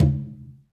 Tumba-HitN_v3_rr1_Sum.wav